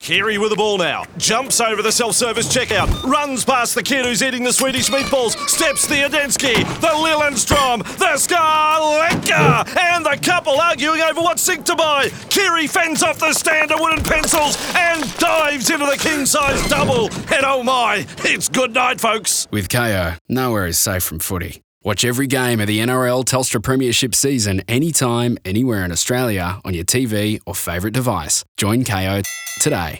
The radio execution narrates Walsh running through a wedding to mark the bouquet toss, and Keary dodging disgruntled shoppers at a furniture store (that has Swedish meatballs) to dive into a double bed.
Kayo-Radio-Nowhere-is-safe-from-footy-NRL-Furniture-Store-.wav